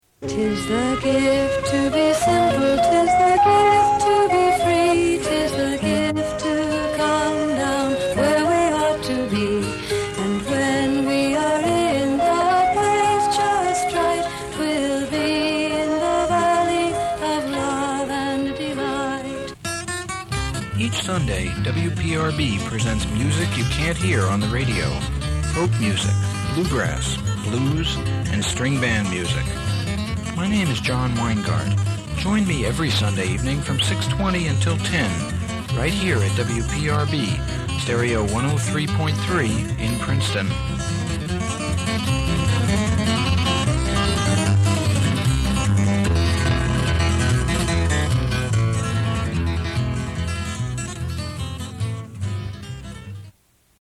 LISTEN: “Music You Can’t Hear on the Radio” promos, 1977
Dating from the mid-late 1970s, these airchecks were on both 1/4″ reel as well as cassette, and unlike most of PRB’s native collection, were all in perfect condition (no creeping black mold!)